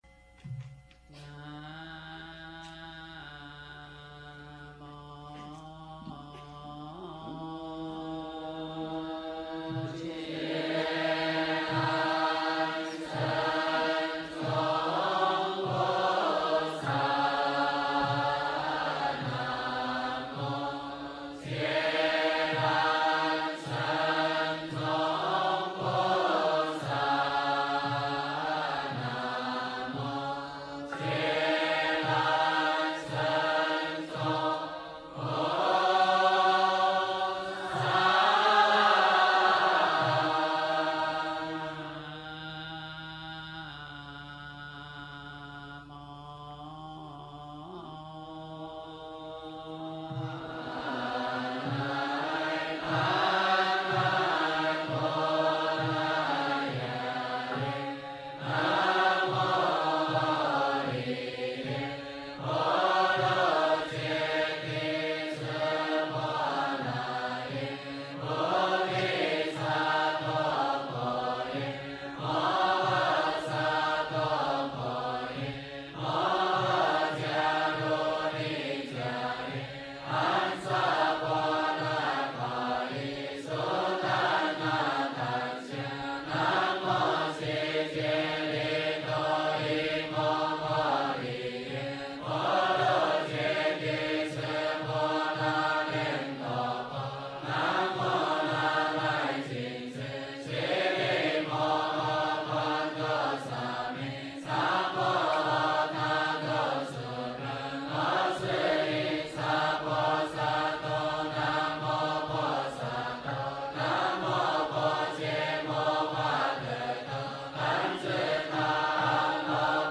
珈蓝赞 诵经 珈蓝赞--未知 点我： 标签: 佛音 诵经 佛教音乐 返回列表 上一篇： 一柱沉檀 下一篇： 大悲咒 相关文章 财神咒(音乐)--新韵传音 财神咒(音乐)--新韵传音...